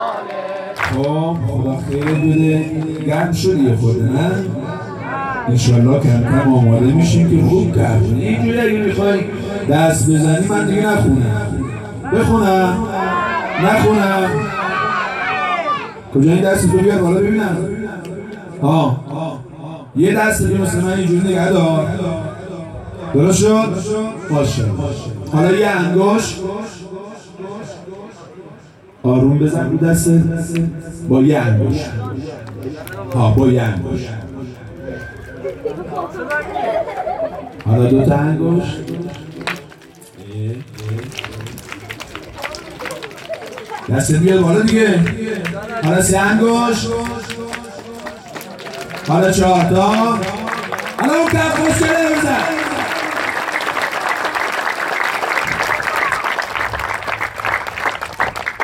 مراسم جشن بزرگ نیمه شعبان98هیئت میثاق الحسین علیه السلام سیستان